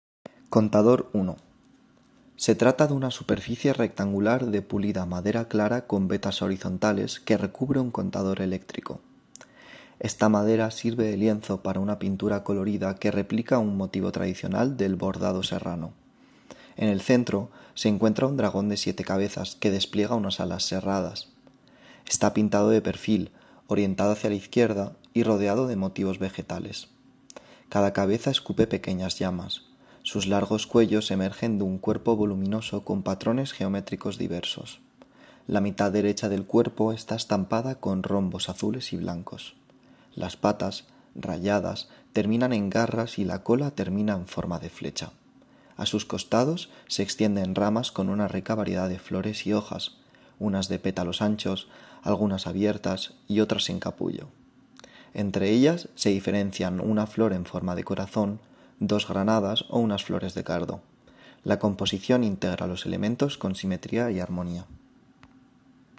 Audiodescripción del contador